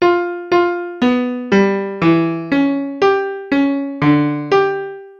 In diesem Codebeispiel werden 10 Noten mit einem Notenwert zwischen 50 und 70 gespielt.
use_synth :piano